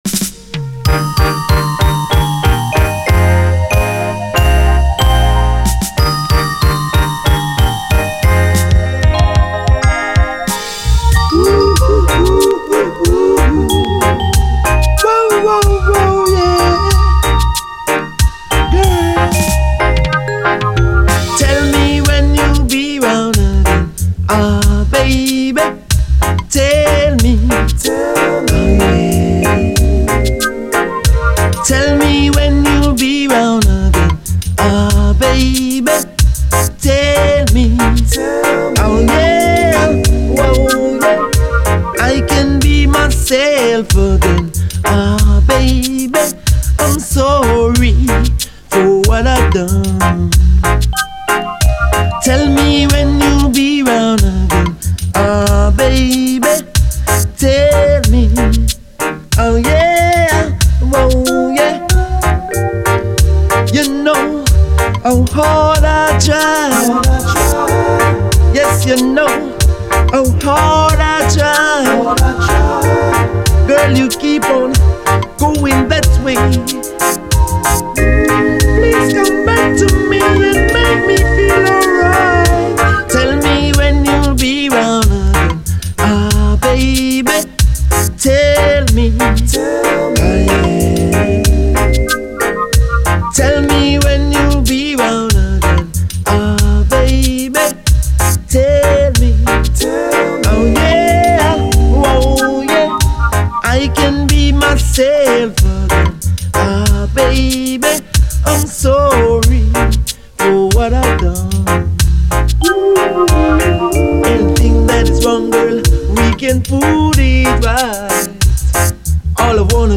REGGAE
陽だまり90’SメロウUKラヴァーズ！